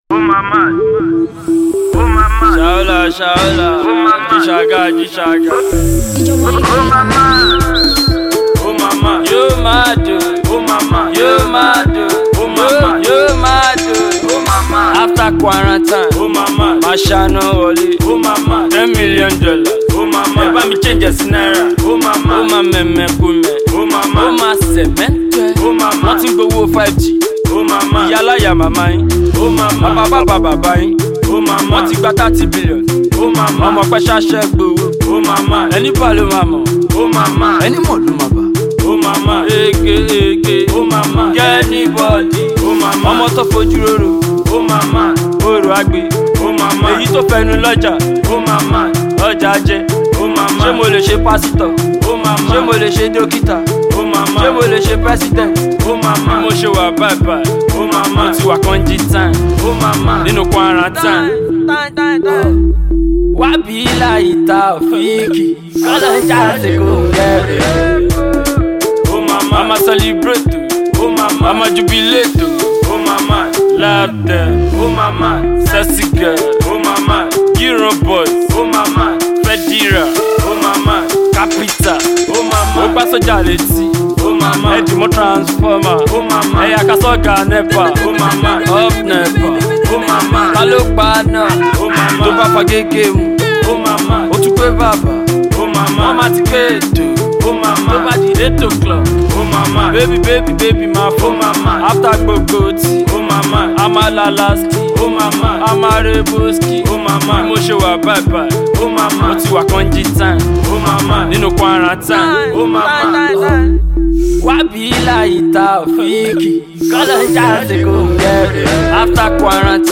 Nigerian rapper
a new anthem for the street
Street vibe producer